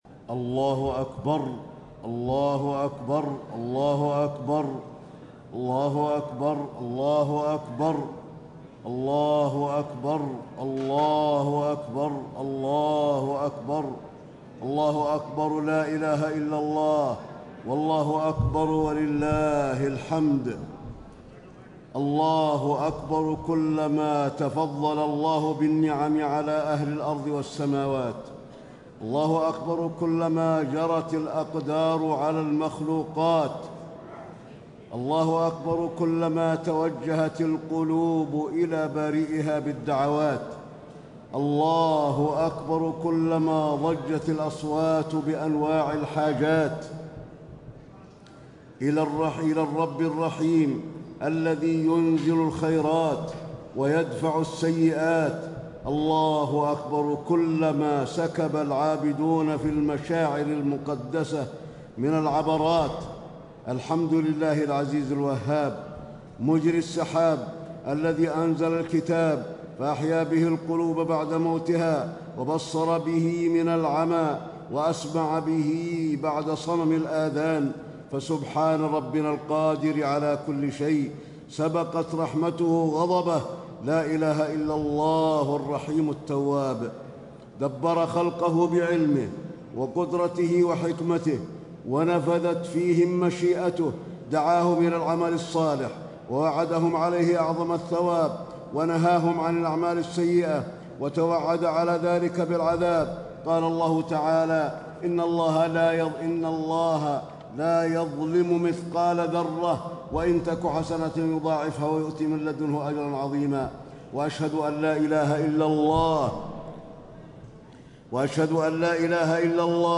خطبة عيد الأضحى - المدينة - الشيخ علي الحذيفي
المكان: المسجد النبوي